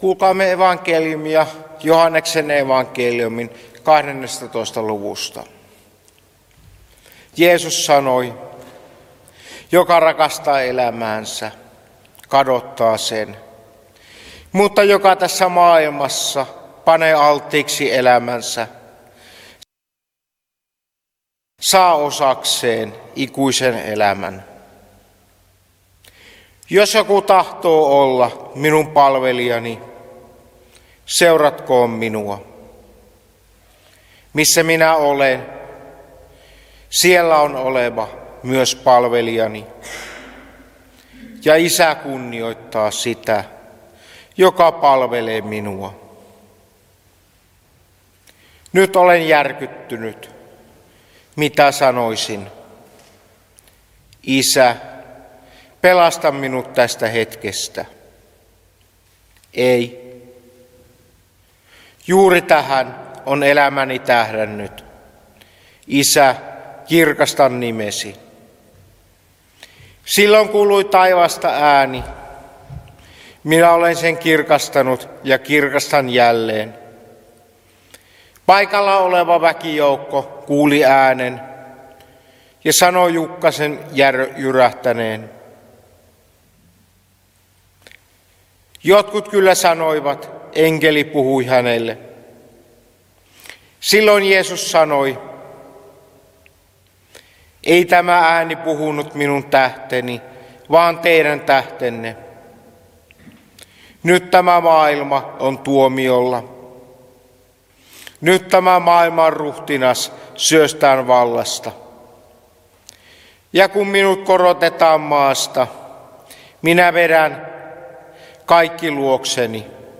paastonajan lauluhetkessä tuhkakeskiviikon jälkeen torstaina